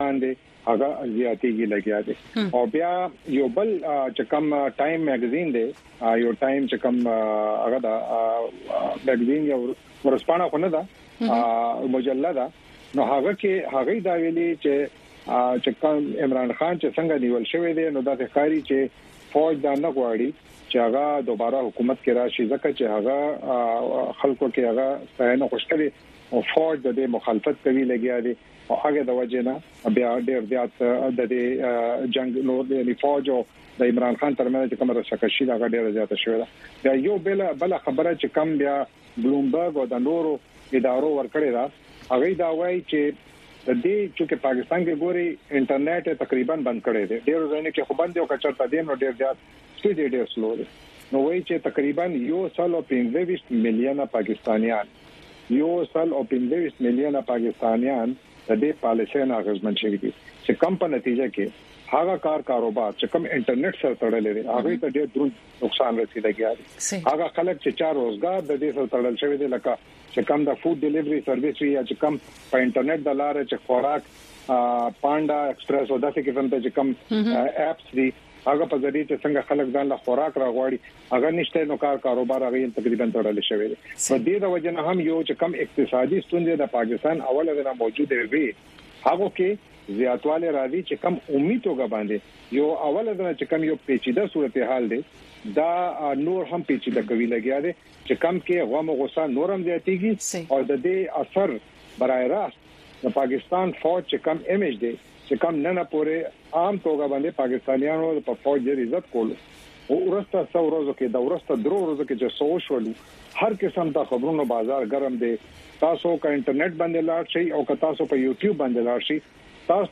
په دې دوؤ ساعتو پروگرام کې تاسو خبرونه او د هغې وروسته، په یو شمېر نړیوالو او سیمه ایزو موضوگانو د میلمنو نه پوښتنې کولی شۍ.